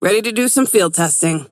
McGinnis voice line - Ready to do some field testing.